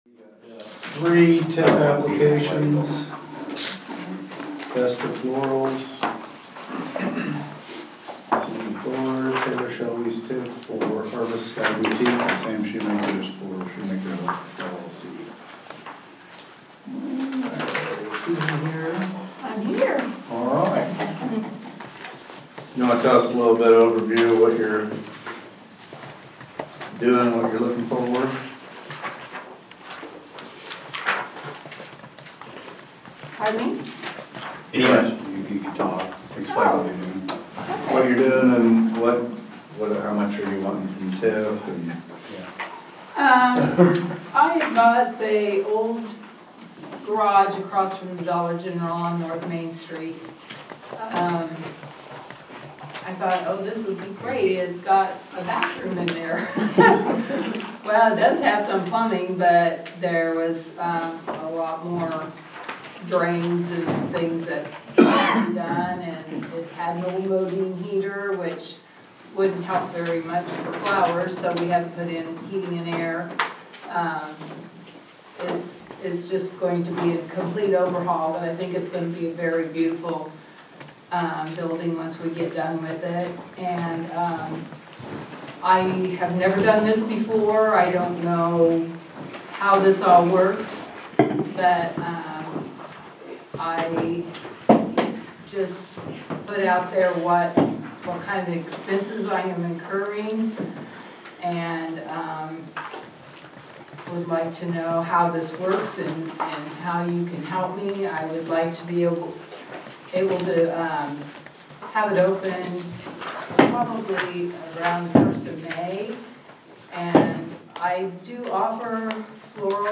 February 25th, 2025 City Council Meeting Audio